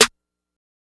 Snare (Gangstas).wav